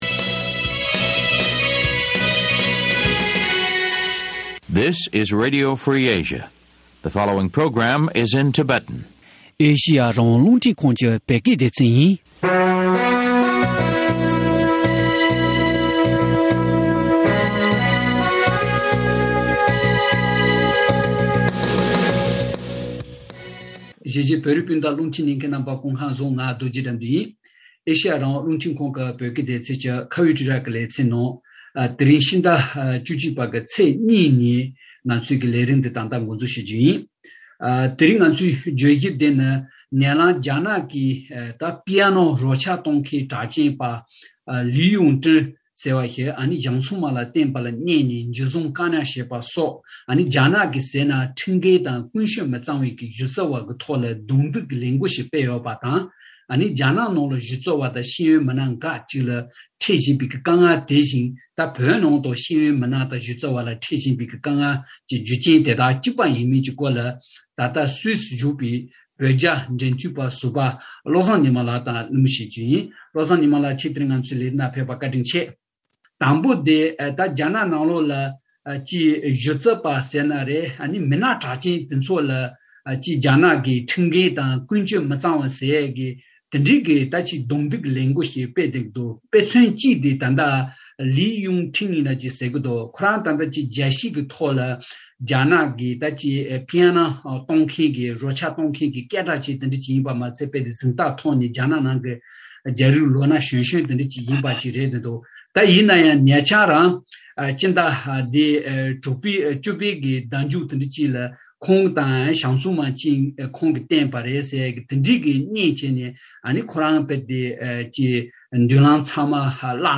བགྲོ་གླེང་བྱས་པར་གསན་རོགས་